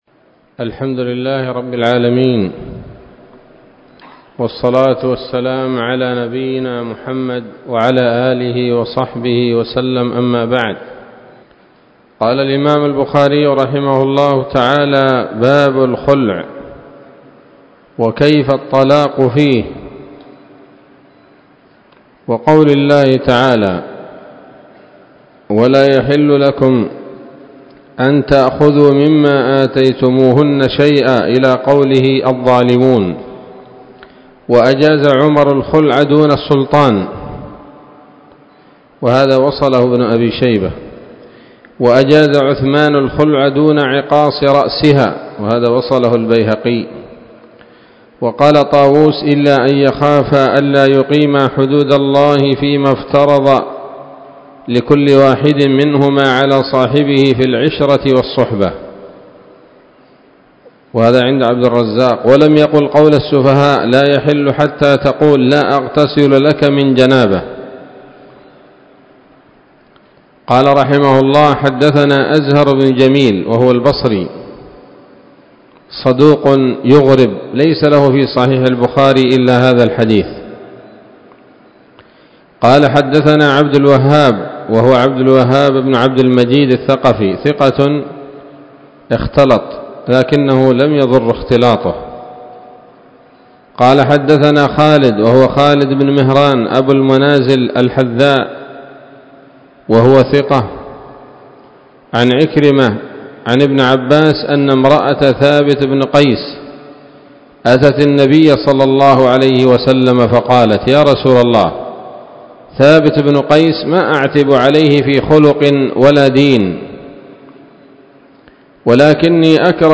الدرس العاشر من كتاب الطلاق من صحيح الإمام البخاري